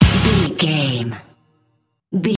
Uplifting
Ionian/Major
drum machine
synthesiser
bass guitar